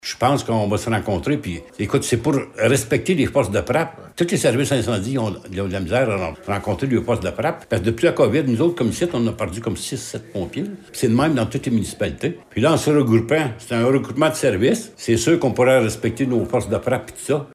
La Municipalité de Blue Sea, lors de son conseil de février, a annoncé être à regarder la possibilité de fusionner son service incendie avec celui de la Municipalité de Bouchette. Le maire de Blue Sea, Laurent Fortin, explique l’avantage majeur de ce possible regroupement :